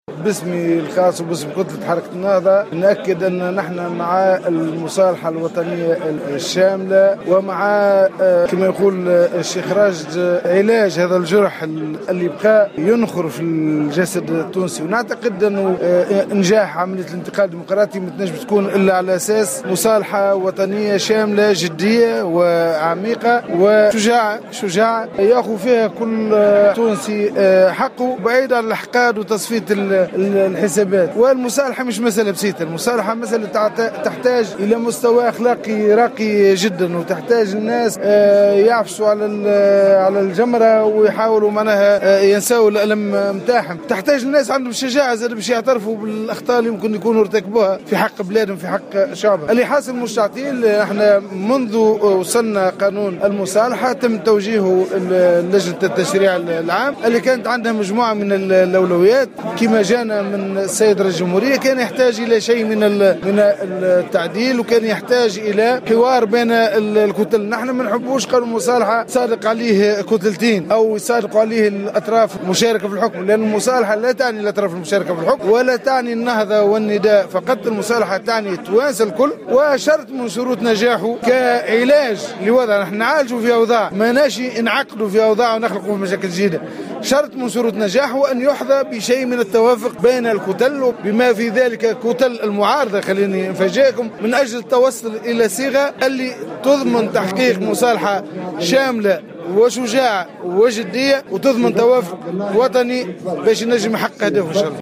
قال النائب عن حركة النهضة بمجلس نواب الشعب نور الدين البحيري في تصريح للجوهرة أف أم اليوم الأربعاء 27 أفريل 2016 إن قانون المصالحة سيمرر بالتوافق بين كل الكتل البرلمانية حتى المعارضة منها وفق تعبيره.